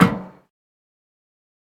step_metal.ogg